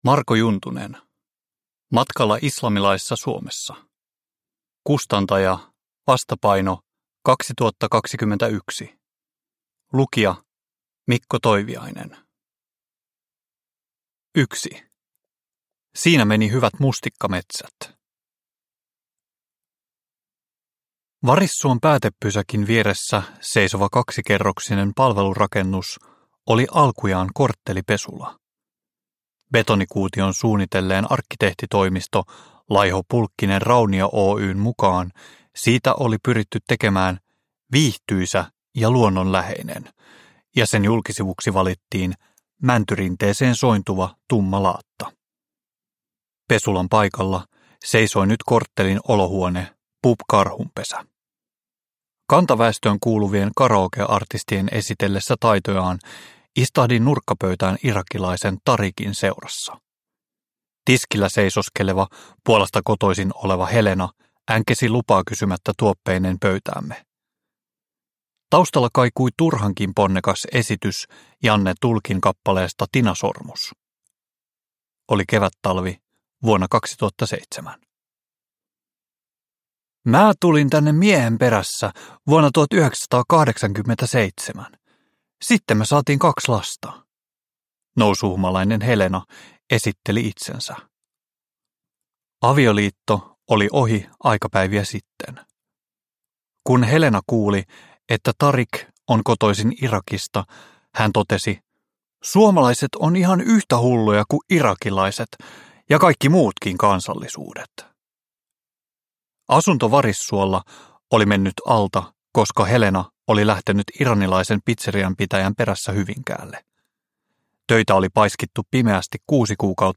Matkalla islamilaisessa Suomessa – Ljudbok – Laddas ner